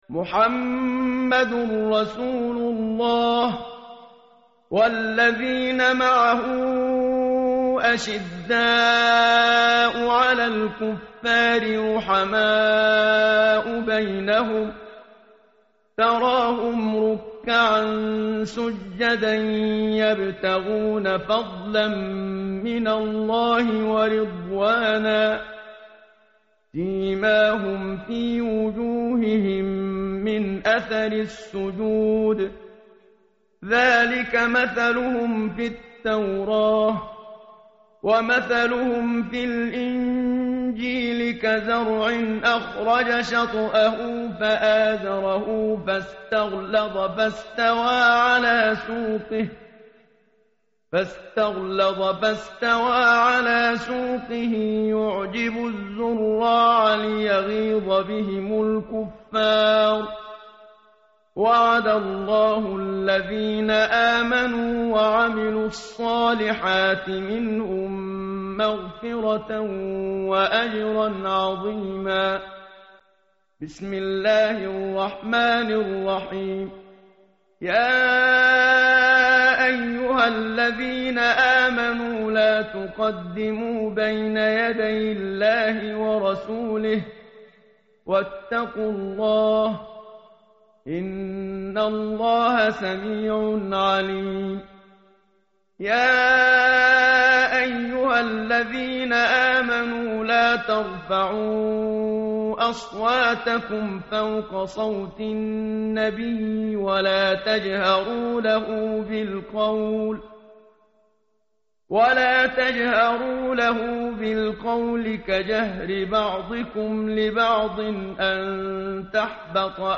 متن قرآن همراه باتلاوت قرآن و ترجمه
tartil_menshavi_page_515.mp3